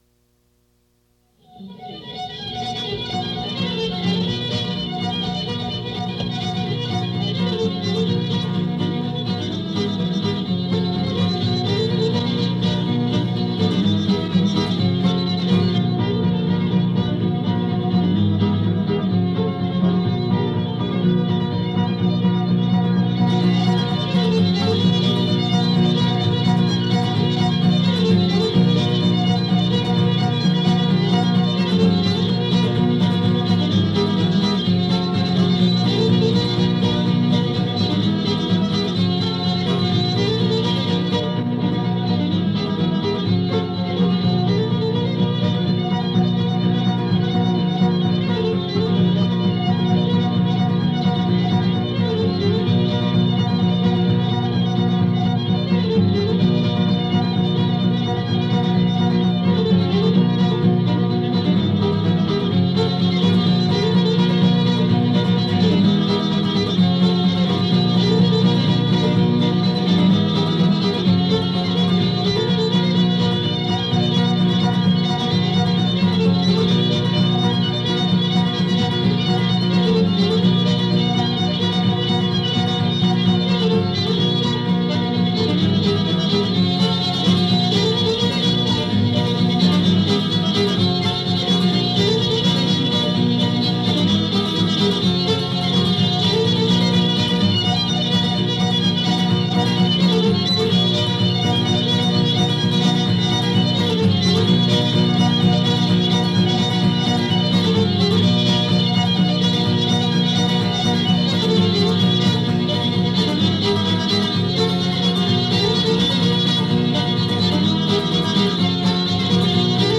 Country music